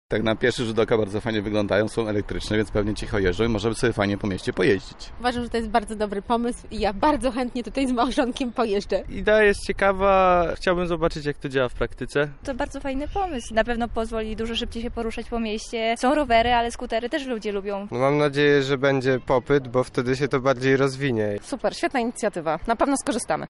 O wrażenia zapytaliśmy mieszkańców miasta: